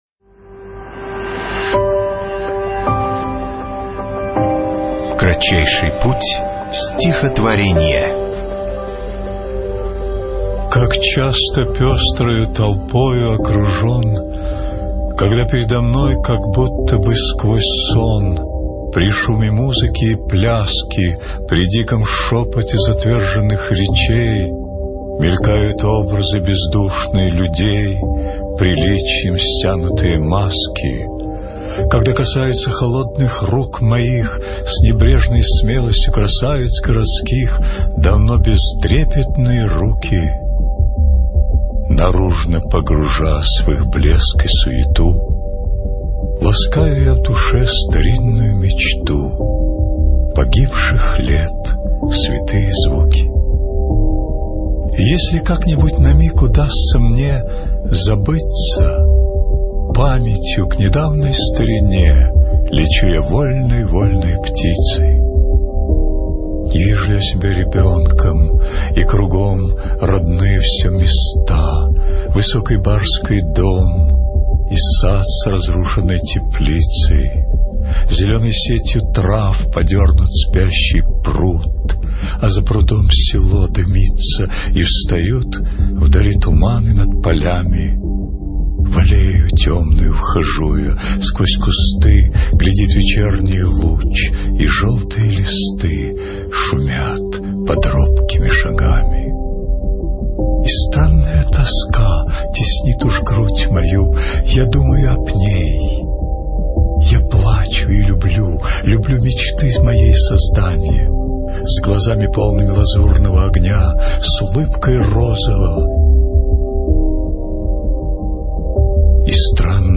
8. «Михаил Лермонтов – Как часто, пестрою толпою окружен… (читает Николай Мартон)» /